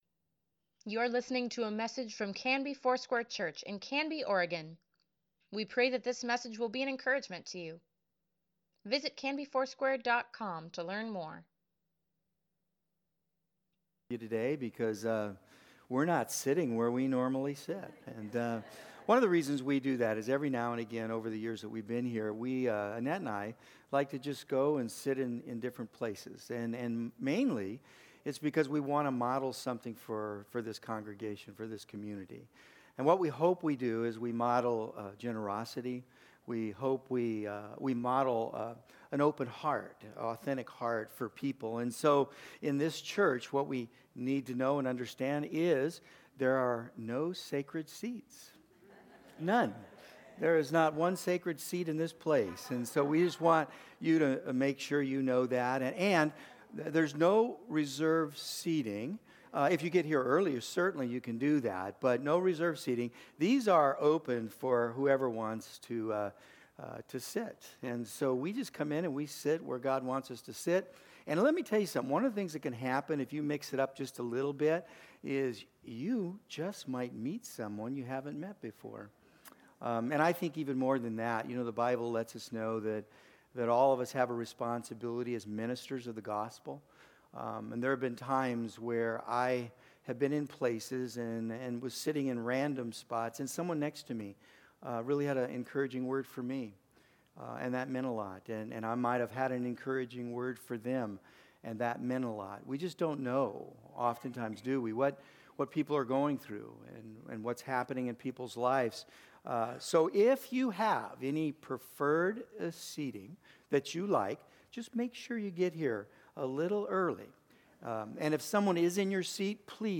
Sunday Sermon | April 21, 2024